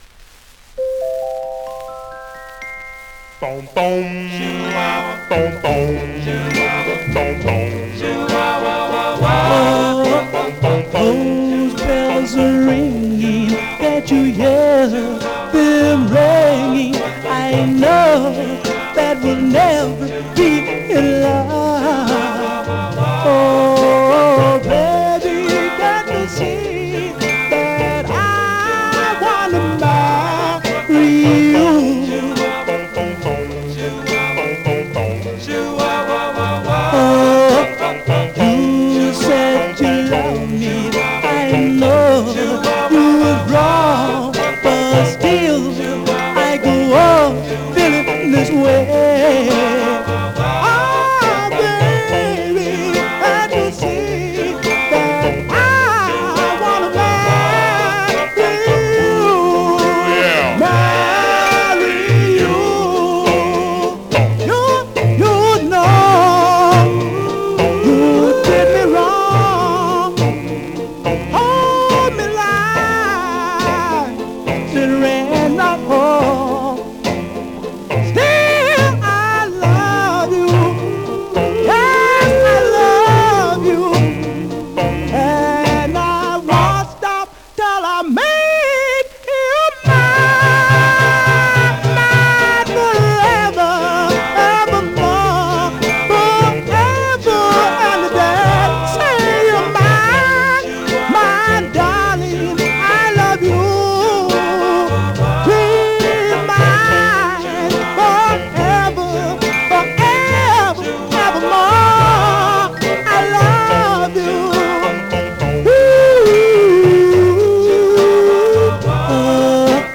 Some surface noise/wear Stereo/mono Mono
Male Black Group